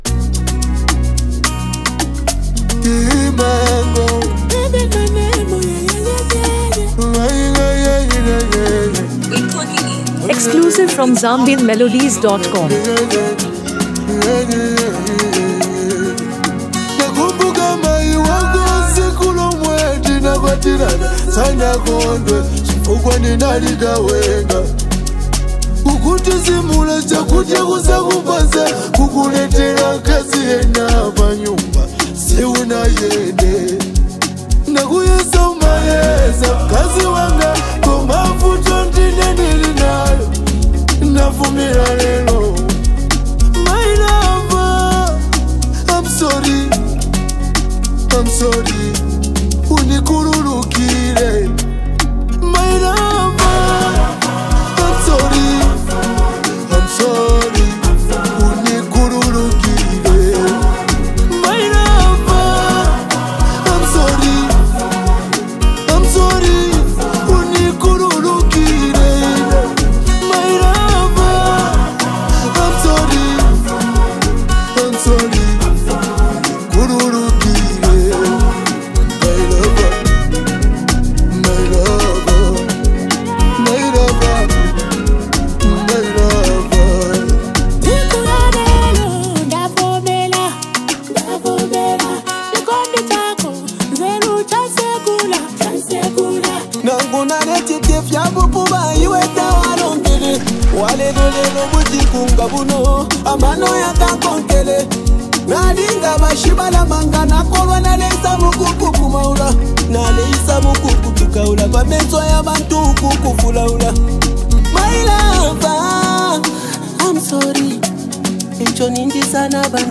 A soulful Zambian song about love, regret & forgiveness.
smooth vocal blend